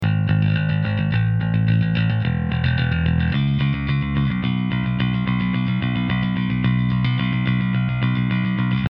Akorát jsem se dostal ke stopám basy, tak tu dávám pro porovnání kousek, rozdíl je tam (mezi mikrofony a linkou - která je ale PRE-EQ, což to porovnání s linkou trochu kazí).
Mikrofon - SM57